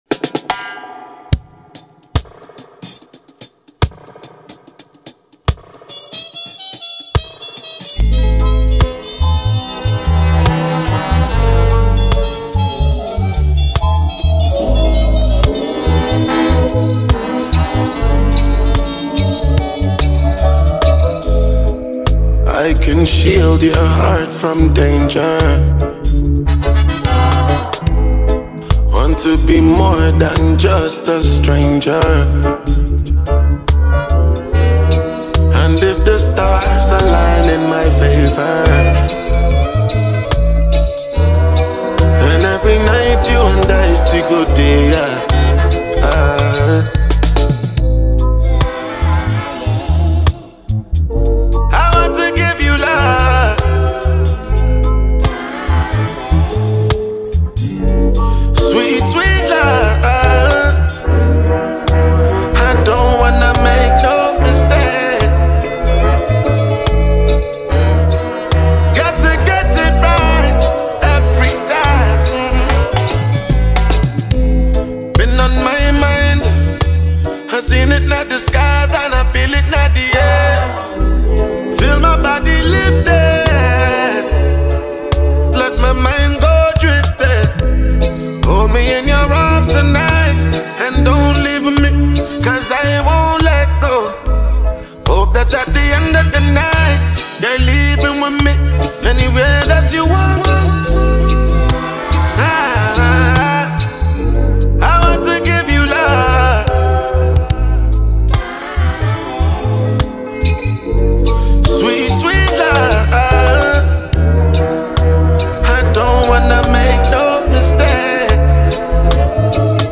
Talented Nigerian singer and songwriter